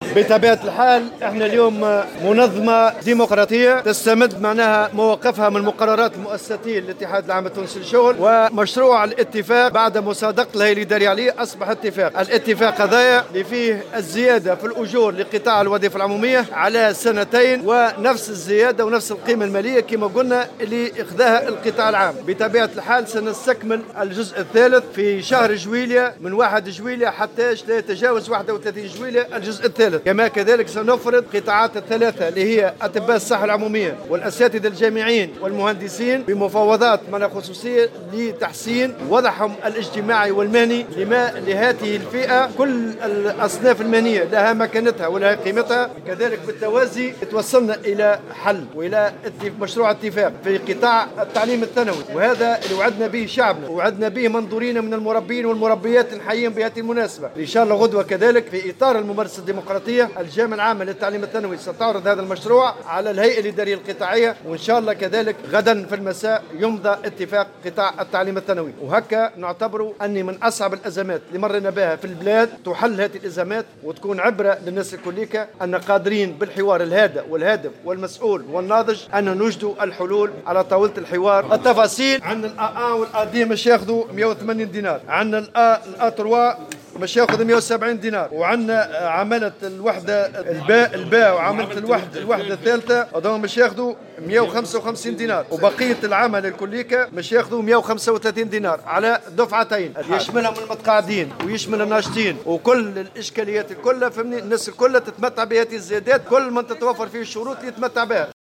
وأضاف في تصريح لمراسل الجوهرة اف ام، أنه سيتم صرف الزيادة على دفعتين الأولى بداية من ديسمبر 2018، مع مفعول رجعي يصرف في مارس القادم، والدفعة الثانية على قسطين الأول في جويلية 2019 والثاني في غرة جانفي 2020.